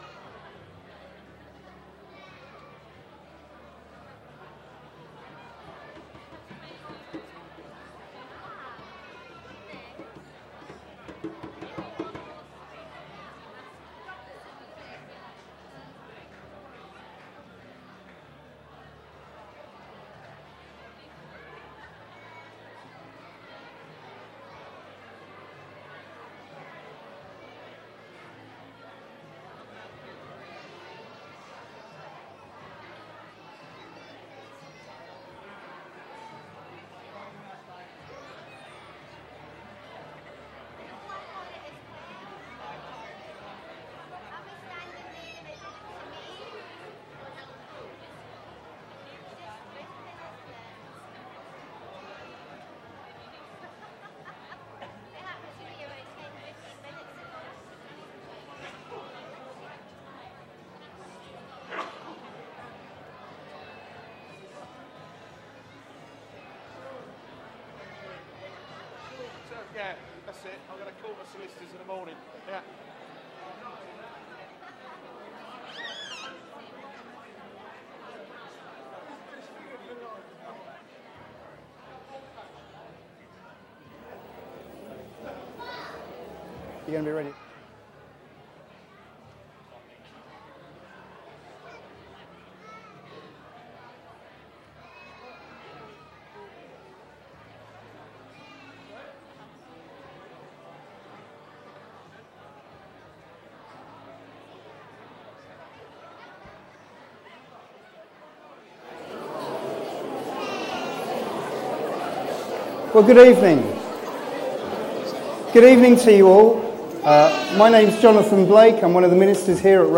5pm Candlit Family Carol Service